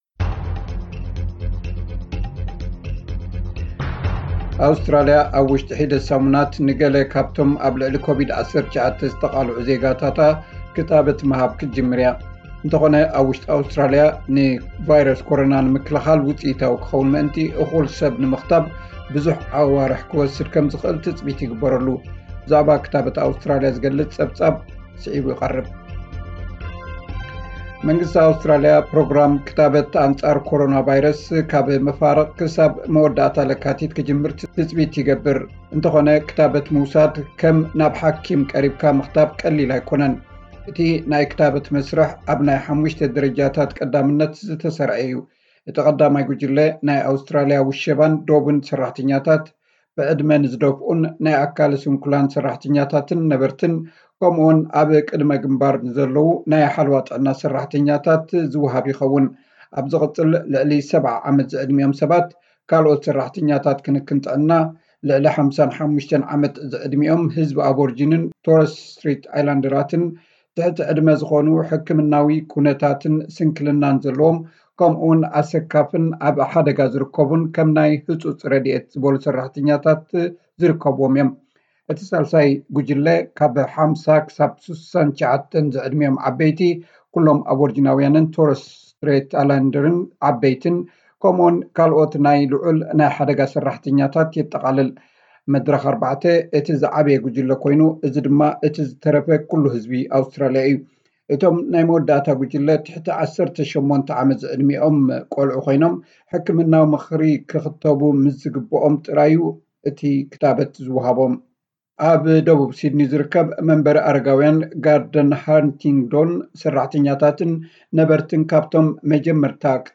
ኣውስትራሊያ ኣብ ውሽጢ ሒደት ሰሙናት ንገለ ካብቶም ኣብ ልዕሊ ኮቪድ-19 ዝተቓልዑ ዜጋታታ ክታበት ምሃብ ክትጅምር እያ። እንተኾነ ኣብ ውሽጢ ኣውስትራሊያ ንቫይረስ ኮሮና ንምክልኻል ውፅኢታዊ ክኸውን ምእንቲ እኹል ሰብ ንምኽታብ ብዙሕ ኣዋርሕ ክወስድ ከምዝኽእል ትጽቢት ይግበረሉ ። ብዛዕባ ክታበት ኣውስትራልያ ዝገልጽ ጸብጻብ ።